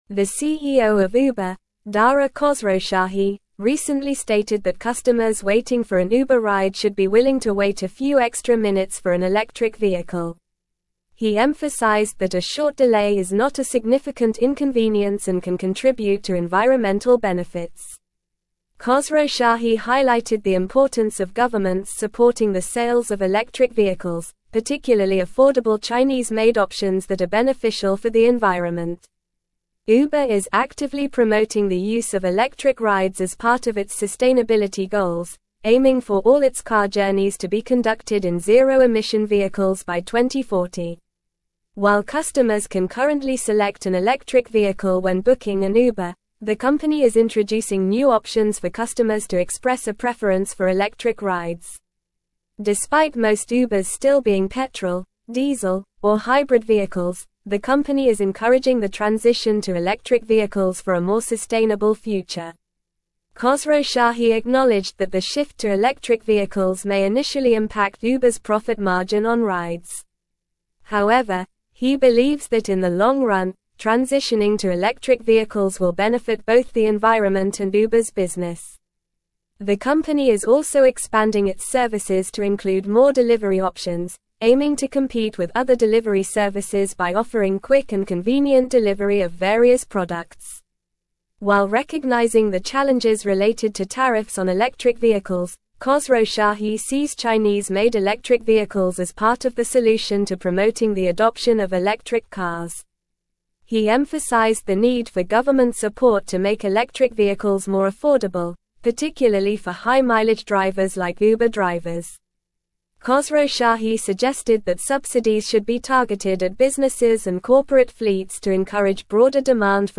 English-Newsroom-Advanced-NORMAL-Reading-Uber-CEO-Urges-Patience-for-Electric-Rides-Transition.mp3